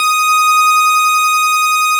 snes_synth_075.wav